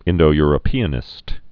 (ĭndō-yrə-pēə-nĭst)